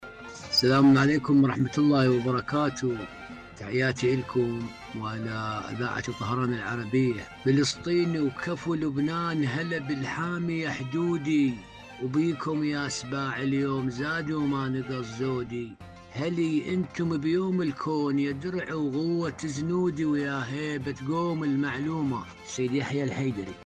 مشاركة صوتية